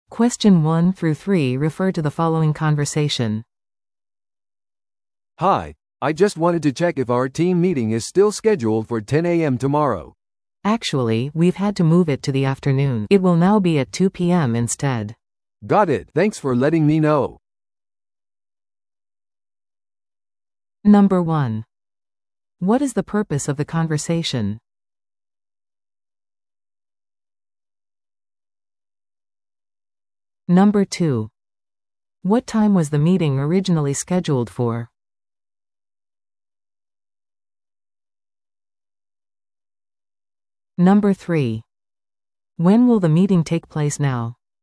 TOEIC PART3の練習問題です。PART3は二人以上の英語会話が流れ、それを聞き取り問題用紙に書かれている設問に回答する形式のリスニング問題です。